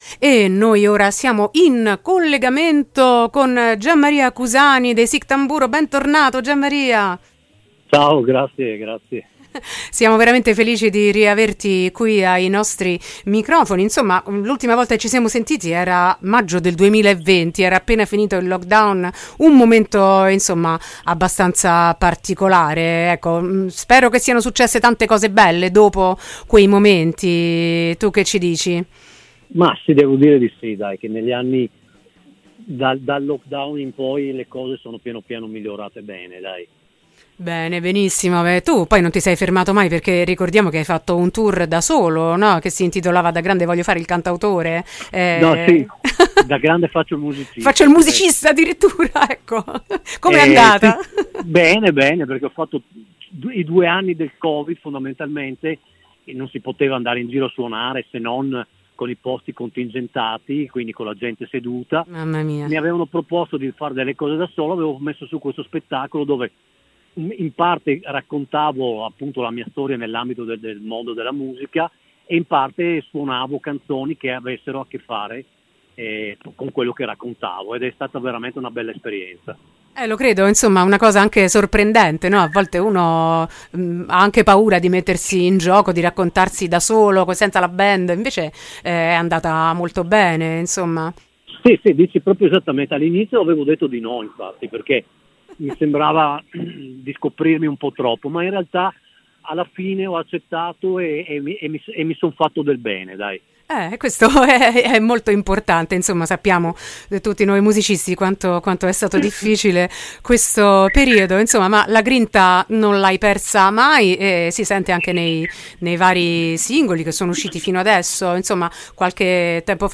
“Il colore si perde”: intervista ai Sick Tamburo | Radio Città Aperta
intervista-sick-tamburo.mp3